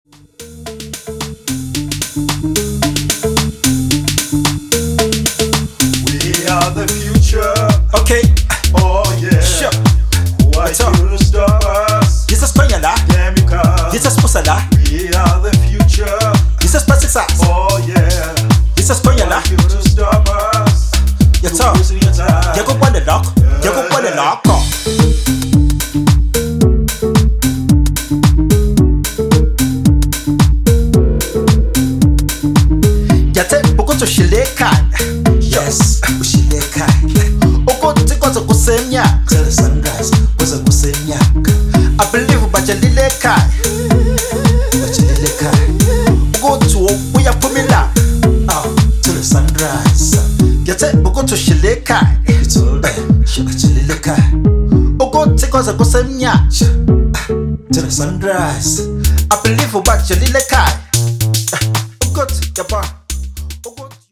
Zulu staccato rap and R&B stylings